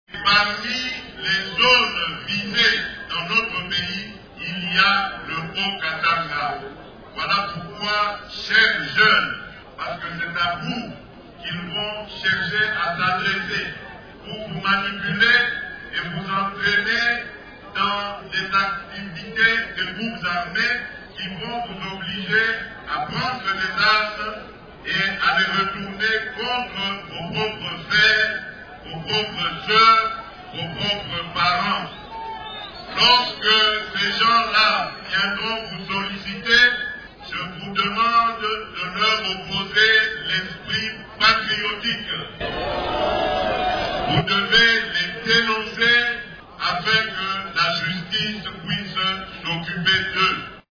Il a lancé cet appel au cours d’un rassemblement qu’il a animé ce dimanche 17 novembre dans cette cité.